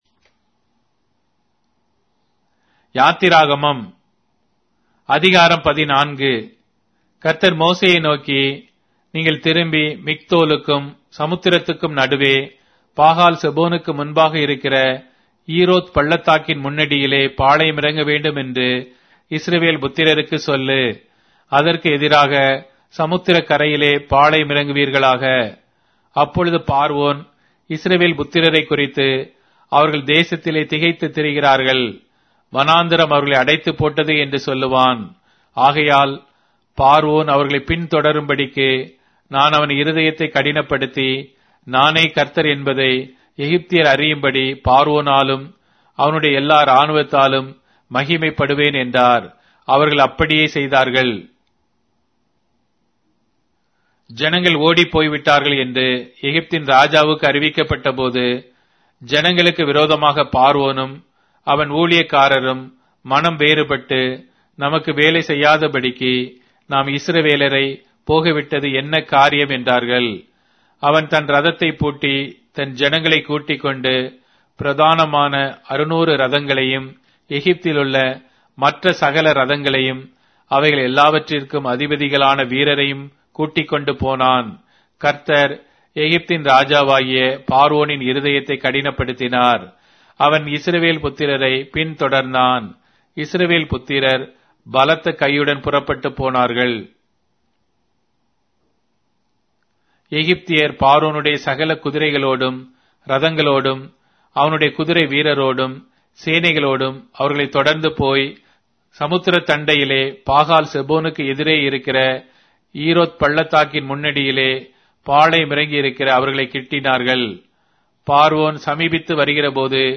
Tamil Audio Bible - Exodus 13 in Nlv bible version